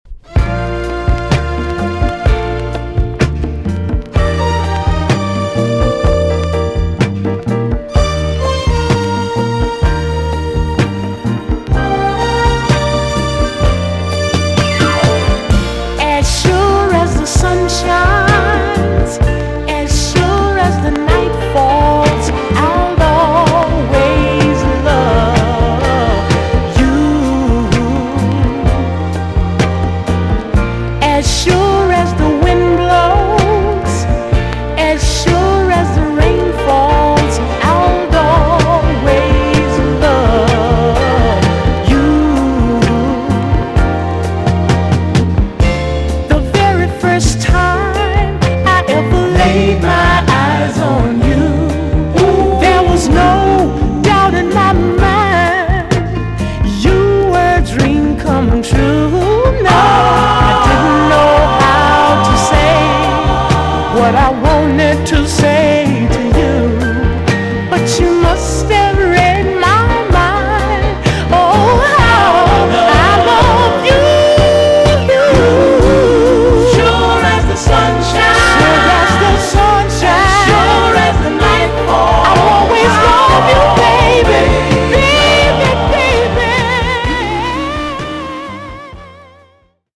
EX-音はキレイです。
1975 , WICKED SWEET SOUL TUNE!!
JAMAICAN SOUL RECOMMEND!!